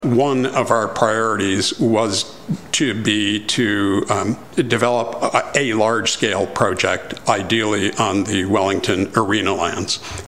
Former Prince Edward County Councillor Treat Hull appeared in front of council at Shire Hall on Tuesday night at their first council meeting of the new year to give a progress update on the what the corporation has been up to.
Hull is the president of the board of directors and told those around the horseshoe about their initial focus.